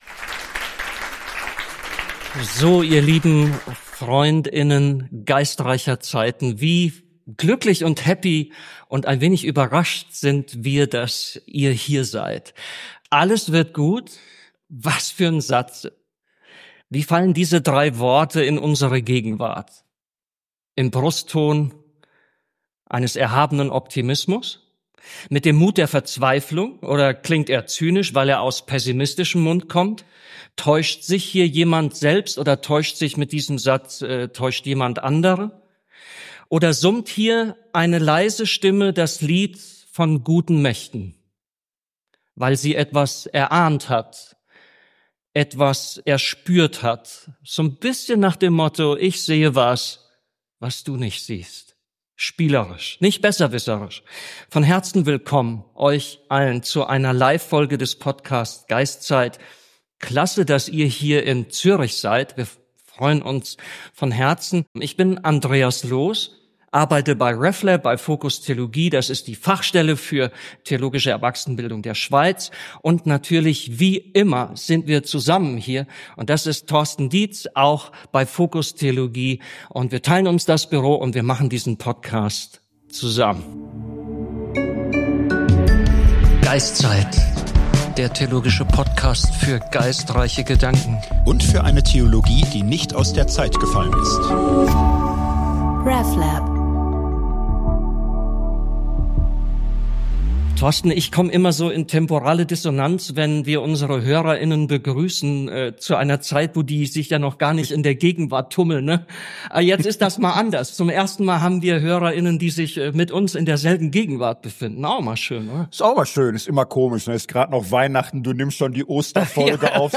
Denn das Publikum hat bei der live Aufnahme starke Fragen gestellt. Ab 01:00:30 beginnt daher eine halbstündige Q&A Session.